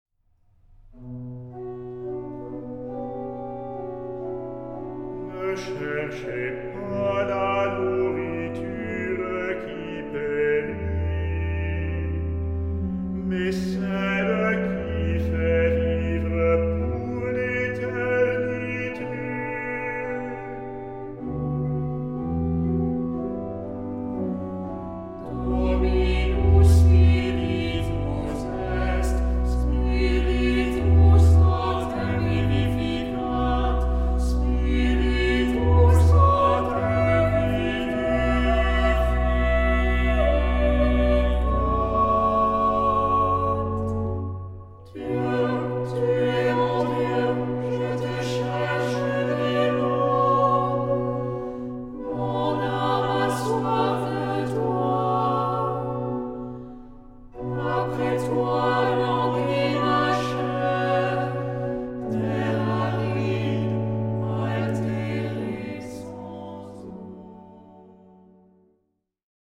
Genre-Style-Forme : Tropaire ; Psalmodie
Caractère de la pièce : recueilli
Type de choeur : SATB  (4 voix mixtes )
Instruments : Orgue (1)
Tonalité : fa dièse mineur
Ténor solo